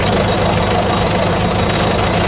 Machine ambient sounds
drill.ogg